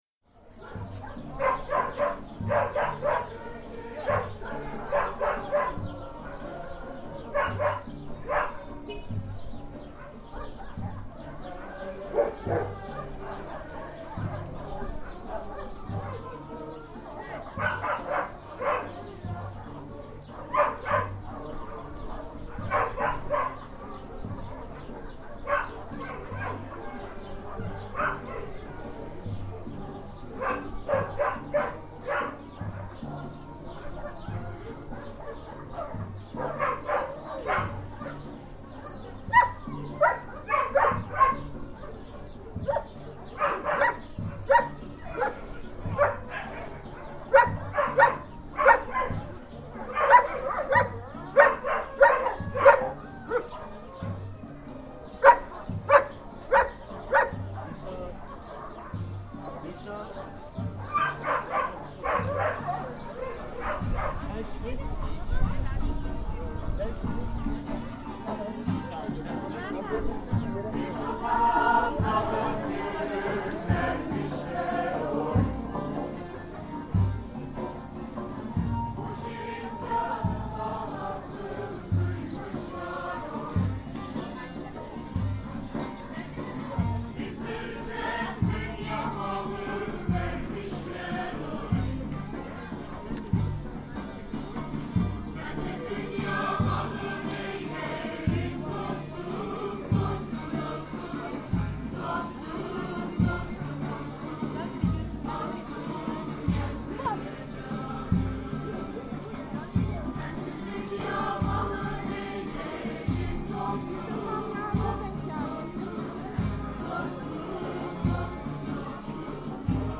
Singing at the animal shelter
Yedikule, a weekend in April
Listen to the fundraiser with the choir singing an Asik Veysel türkü “Dostum”: